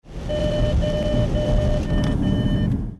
Collision Warning chime (
Chime_collision_avoid.mp3